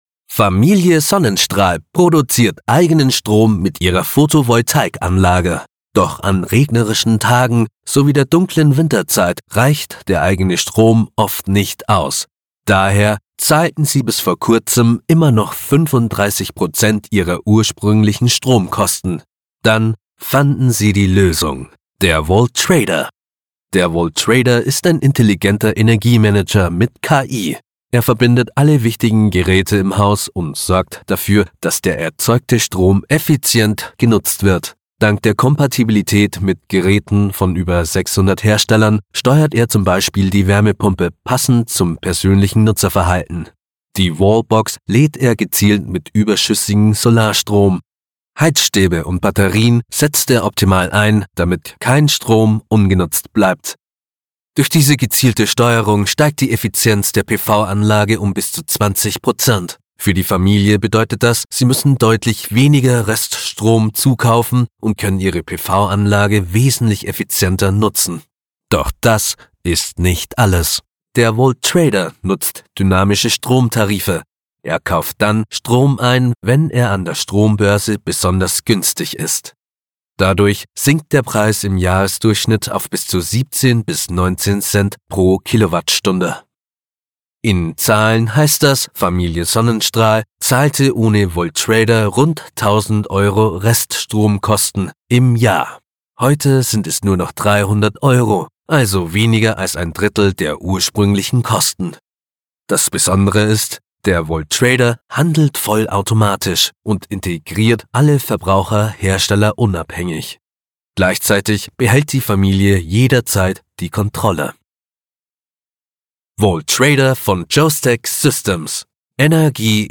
Deep, Cool, Commercial, Natural, Warm
Explainer